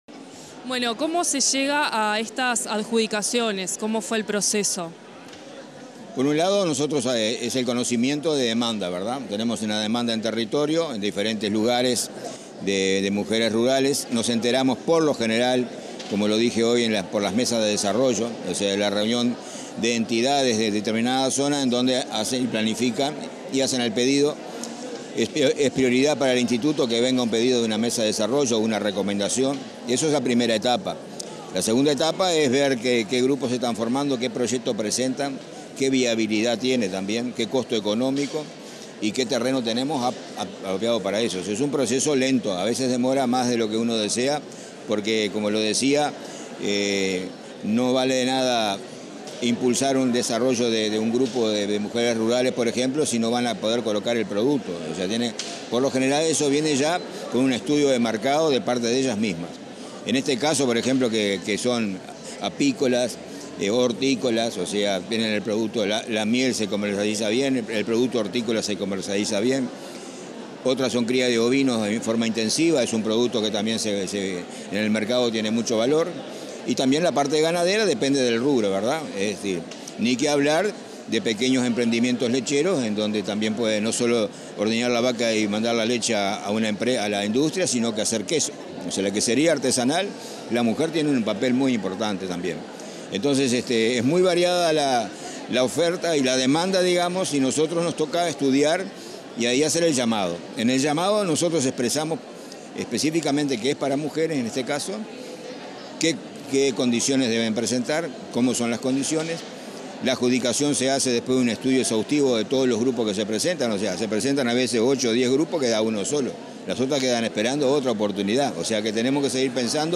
Entrevista al presidente del Instituto Nacional de Colonización, Julio César Cardozo